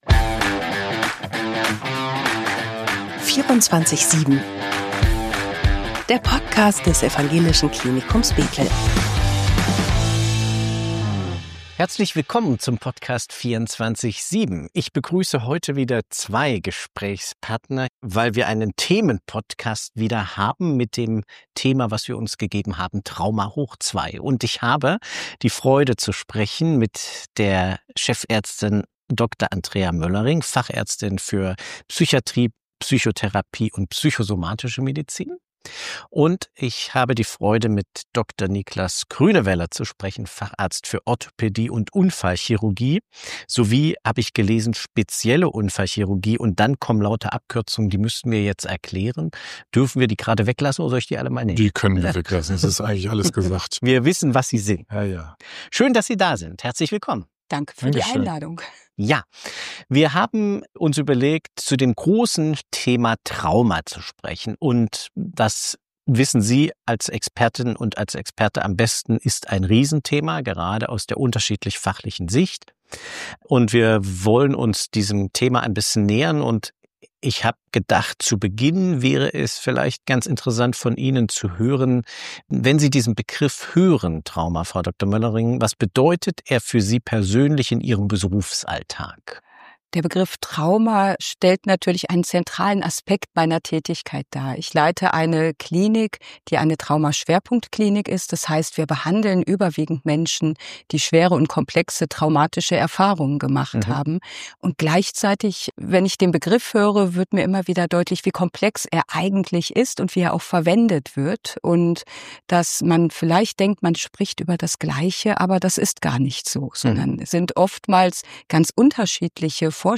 Sie reden über Trauma aus zwei Perspektiven: aus der körperlichen und der psychischen Sicht. Sie zeigen, warum Heilung gut gelingt, wenn Körper und Psyche gemeinsam betrachtet werden, wie Patientinnen und Patienten nach schweren Unfällen begleitet werden und weshalb interdisziplinäre Zusammenarbeit dabei eine zentrale Rolle spielt.